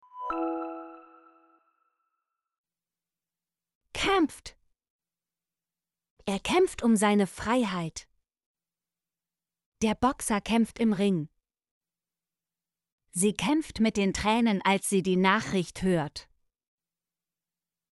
kämpft - Example Sentences & Pronunciation, German Frequency List